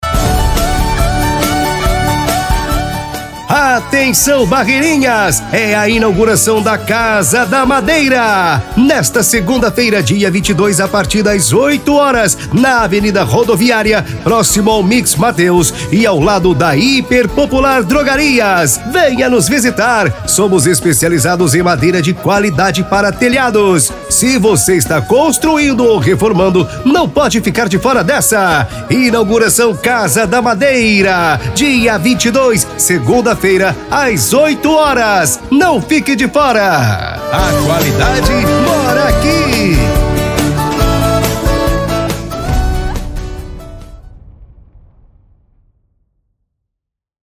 ELA ACHOU MUITO PAPAGAIADO KKK, FALOU ATÉ QUE PARECEU VOZ DE CIRCO
chamada_casa_da_madeirainaugurao.mp3